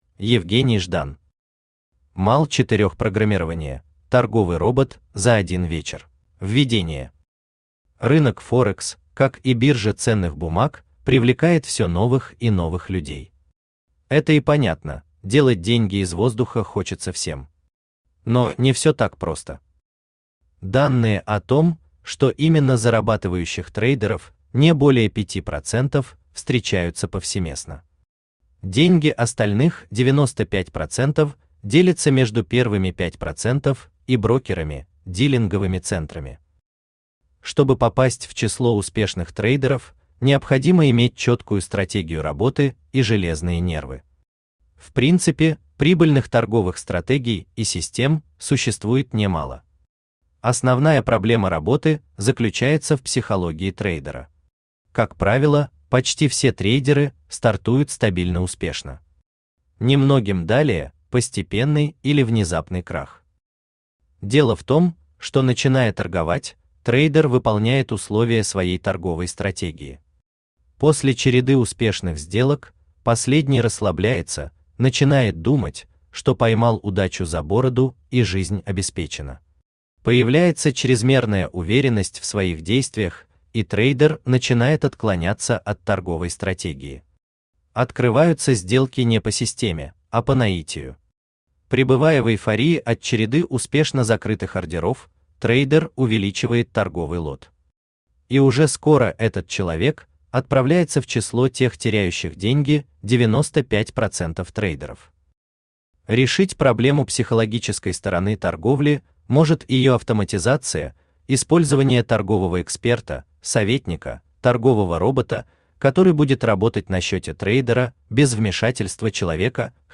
Читает аудиокнигу Авточтец ЛитРес.